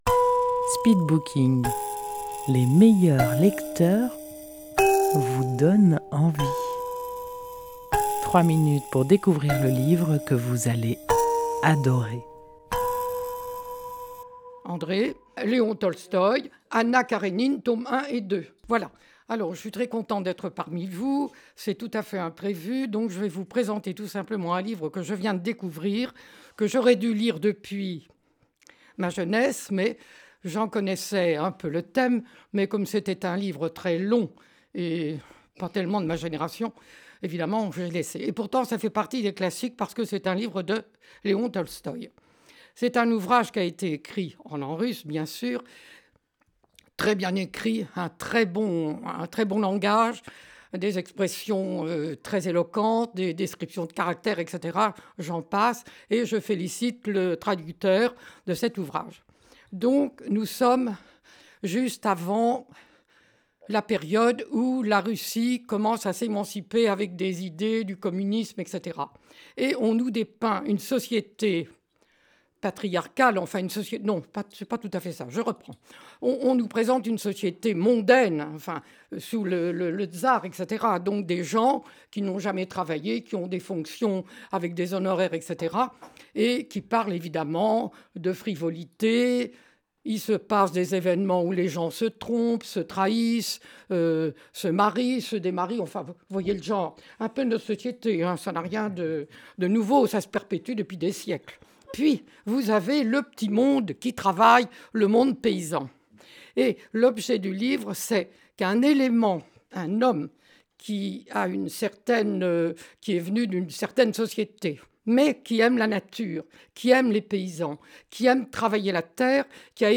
Rendez-vous au speed booking : les meilleurs lecteurs vous font partager leur passion pour un livre en 3 minutes chrono. Enregistré en public au salon de thé Si le cœur vous en dit à Dieulefit.